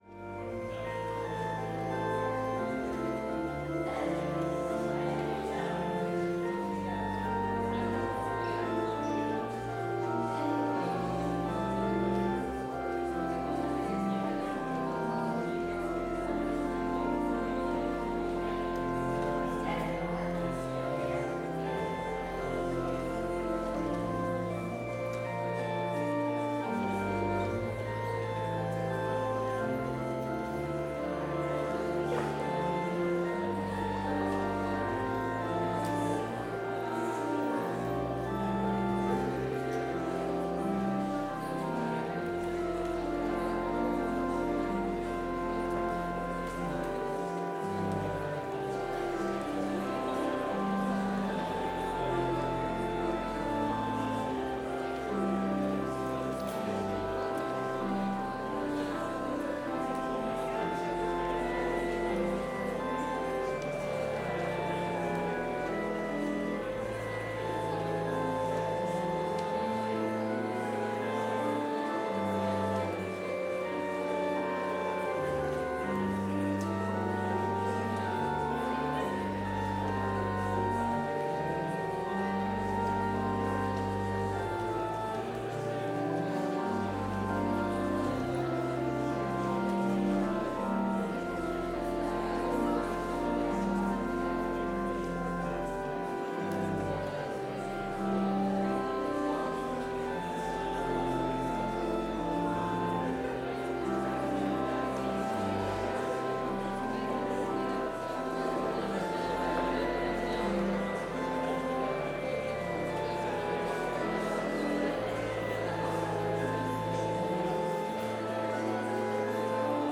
Chapel service in Bethany Lutheran College's chapel, November 6, 2024
Complete service audio for Chapel - Wednesday, November 6, 2024